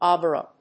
/ˈɑbrʌ(米国英語), ˈɑ:brʌ(英国英語)/